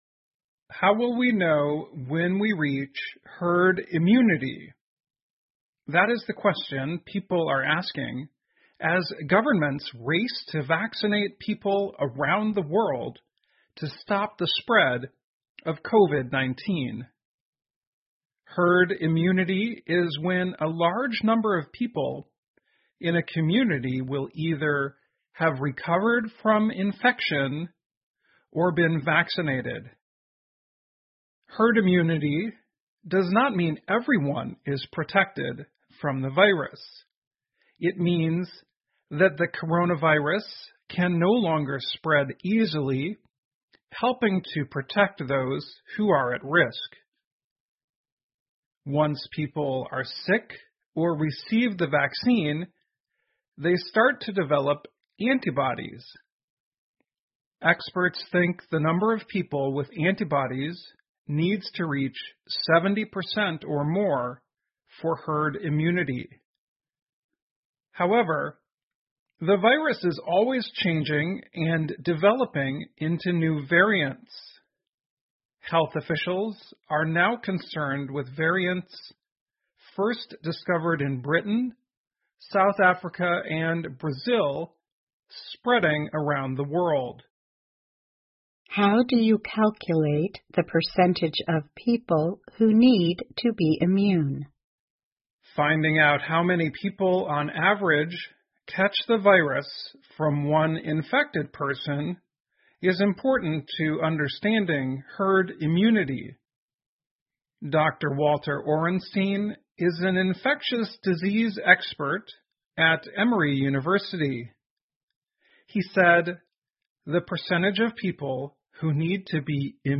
VOA慢速英语2021--我们怎么知道什么时候达到群体免疫? 听力文件下载—在线英语听力室